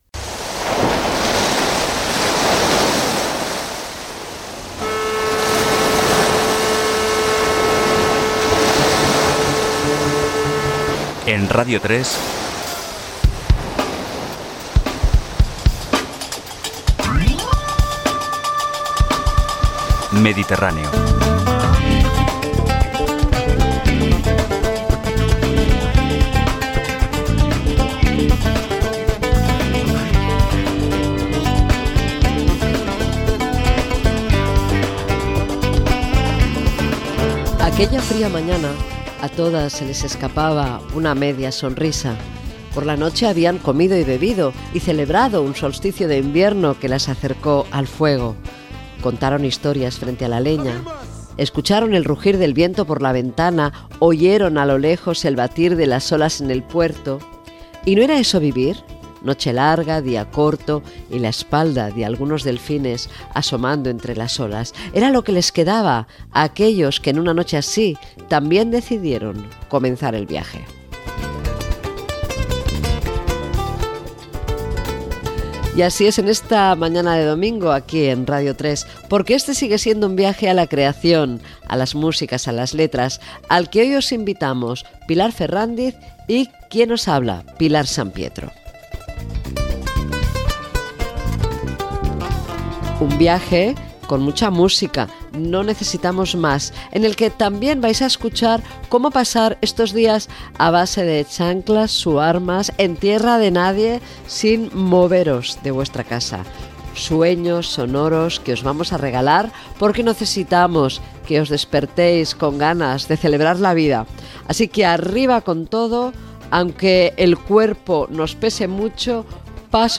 Careta del programa, presentació, tema musical, Zanzíbar (Tanzània), tema musical, comentari i tema musical